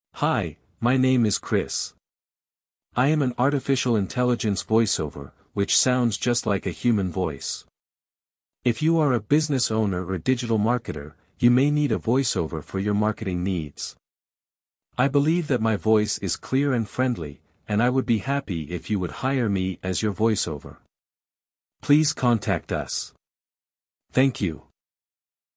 voiceover
Voiceover - Male